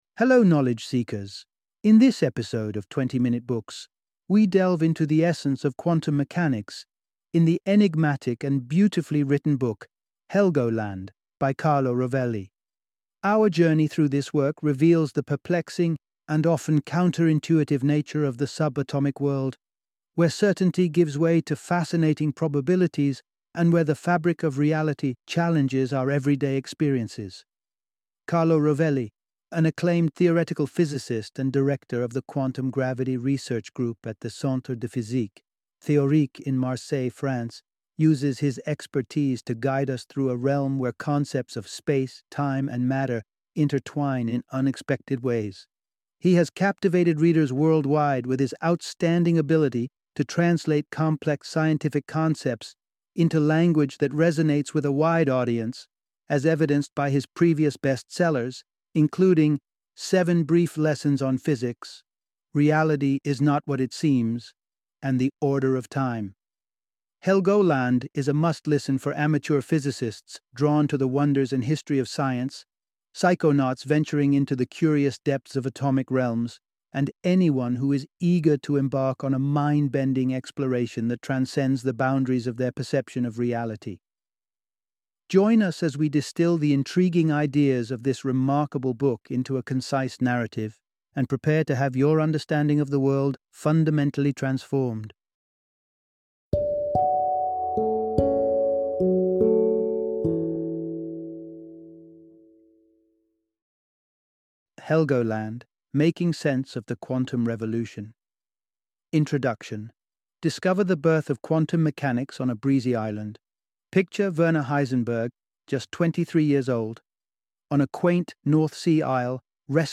Helgoland - Audiobook Summary